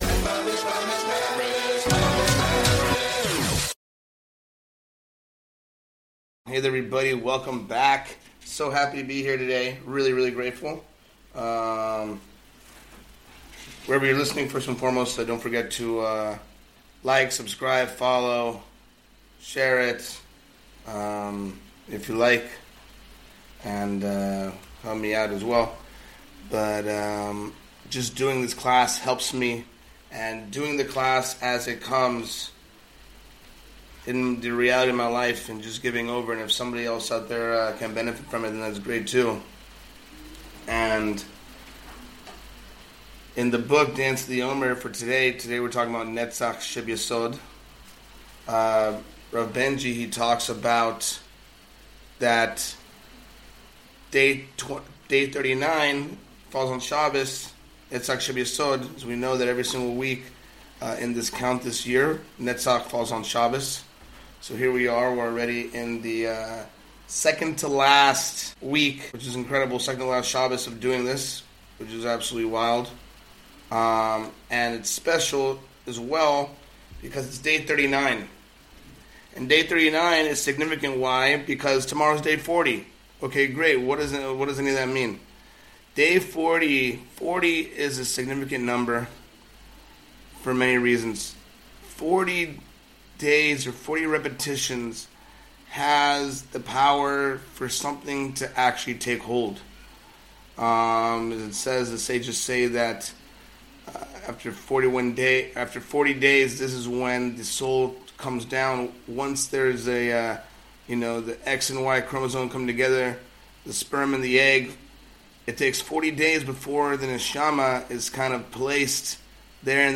In this shiur